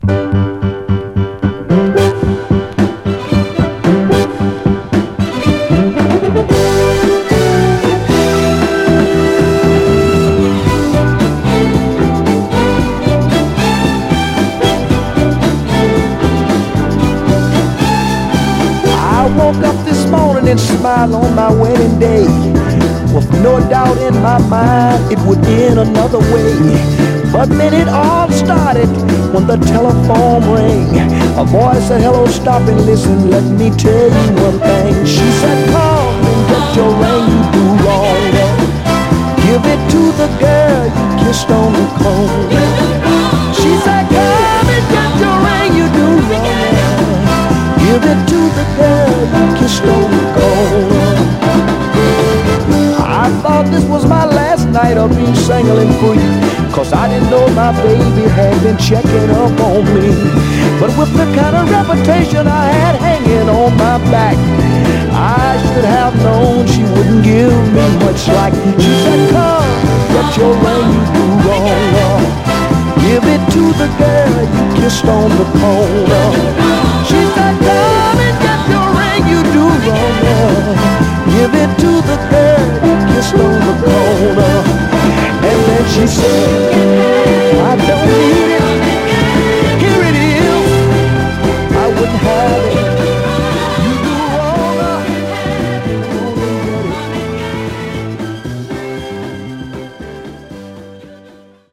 シカゴ・ソウルを象徴する名バリトン・シンガーの一人
こちらも文句なしの高揚感を持った溌剌クロスオーヴァー/ノーザン・ダンサーで良いです！
タフなヴァイナル・プレス、モノ/ステレオ収録のホワイト・プロモ盤。
※試聴音源は実際にお送りする商品から録音したものです※